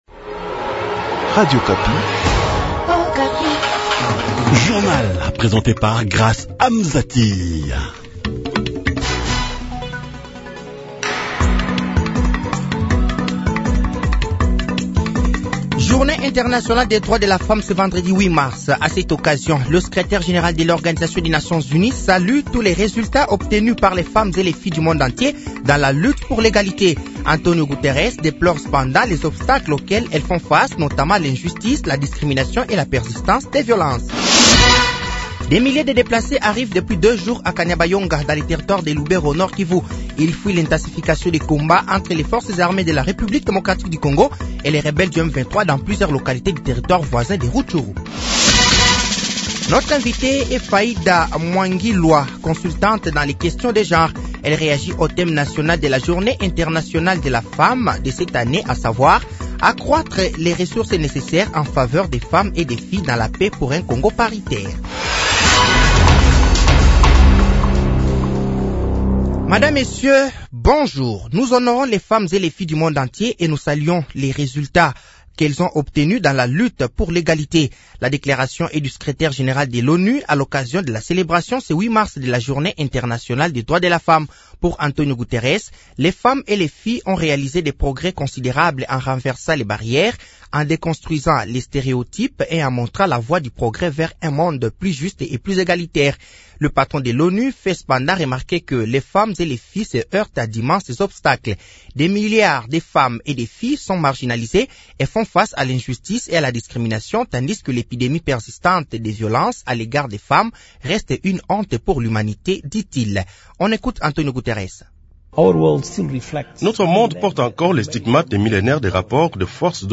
Journal français de 6h de ce vendredi 08 mars 2024